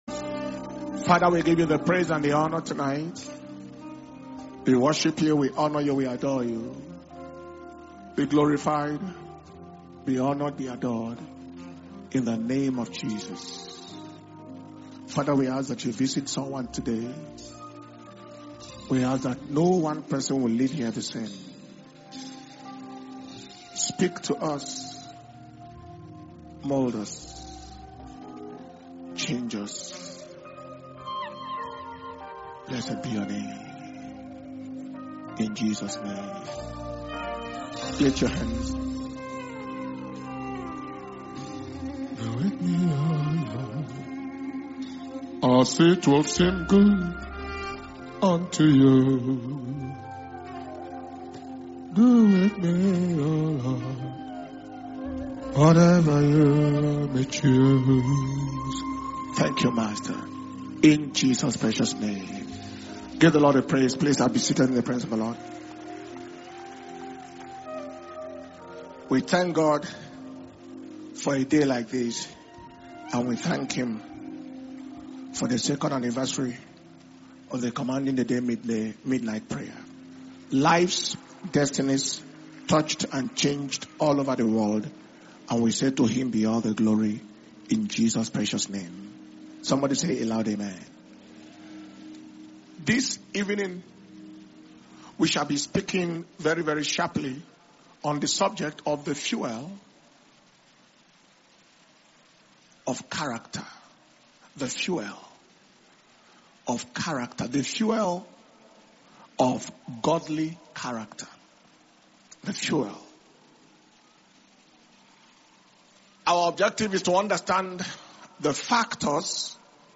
Power Communion Service